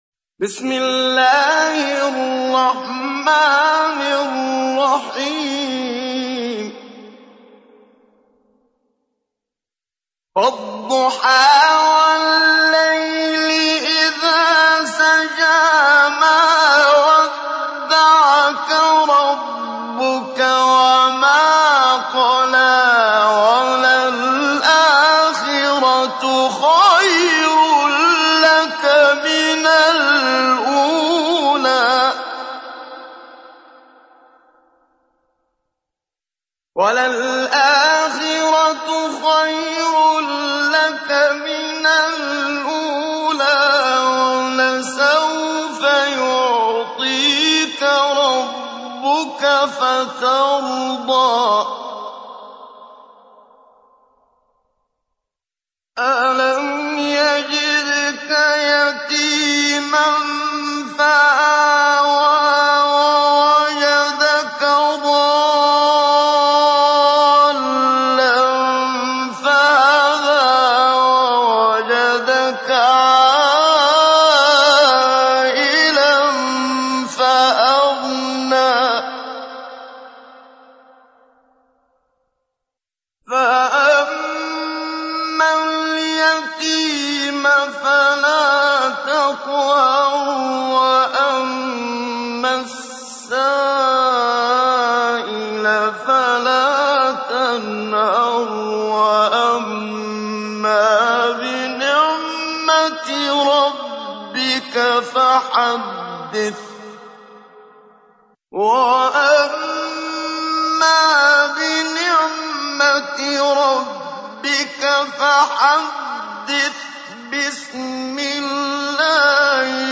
تحميل : 93. سورة الضحى / القارئ محمد صديق المنشاوي / القرآن الكريم / موقع يا حسين